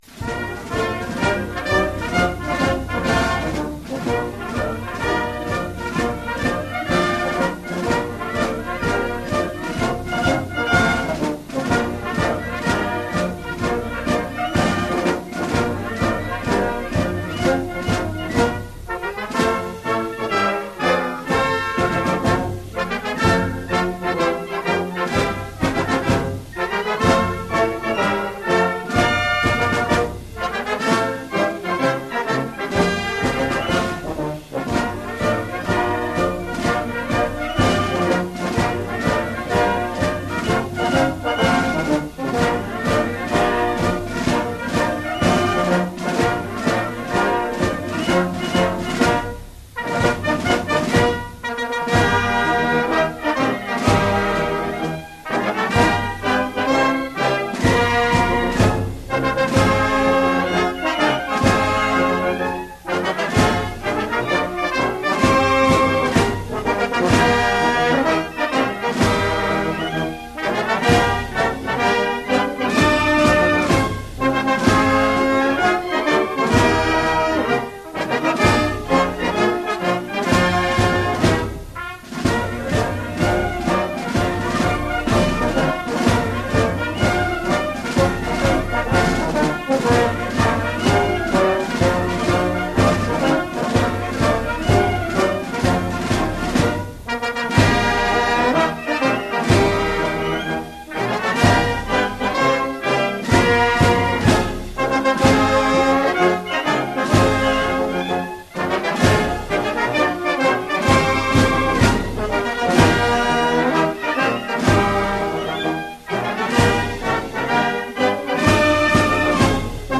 Marche-parade pour
harmonie ou fanfare, avec
tambours et clairons ad lib.